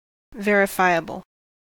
Ääntäminen
Synonyymit attestable Ääntäminen US Haettu sana löytyi näillä lähdekielillä: englanti Määritelmät Adjektiivit Able to be verified or confirmed .